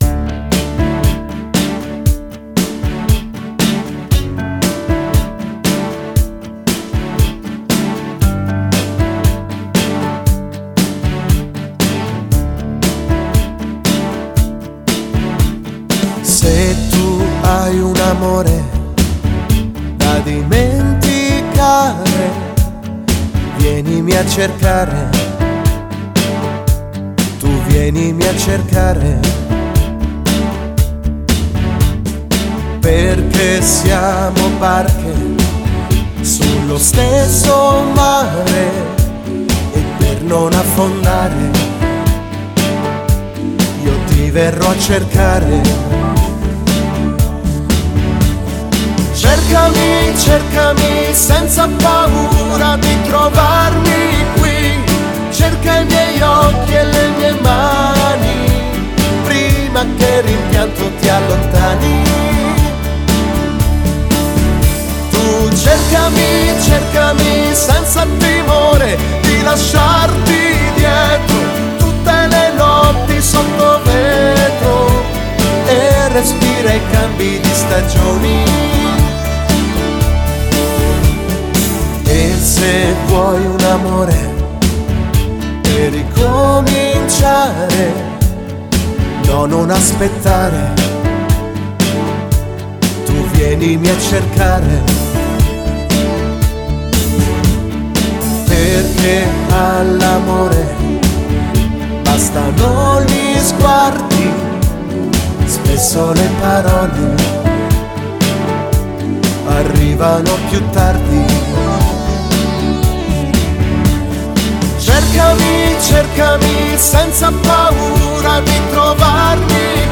Moderato
Bellissima canzone pop, ballabile a passeggiata lenta.